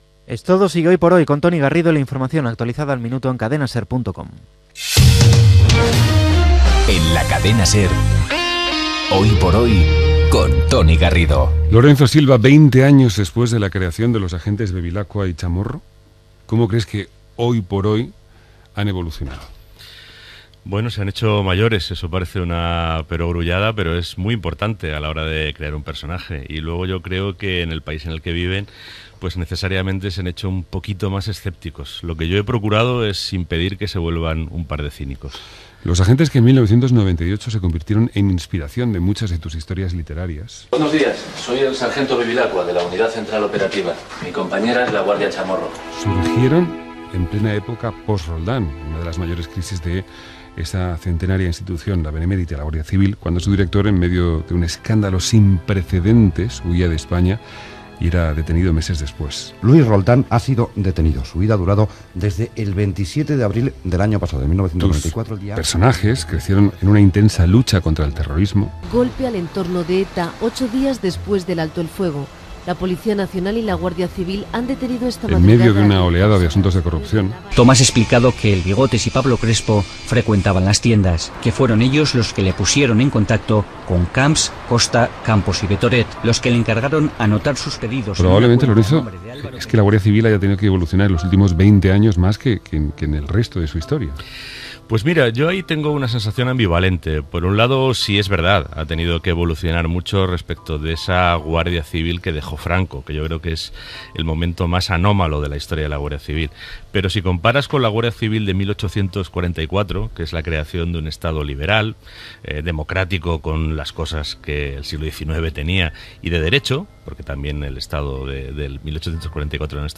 Indicatiu del programa, entrevista a l'advocat i escriptor Lorenzo Silva que presenta "Lejos del corazón". Indicatiu del programa, publicitat
Info-entreteniment